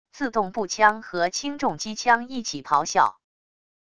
自动步枪和轻重机枪一起咆哮wav音频